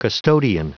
Prononciation du mot custodian en anglais (fichier audio)
Prononciation du mot : custodian